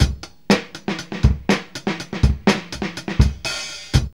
RING HAT 122.wav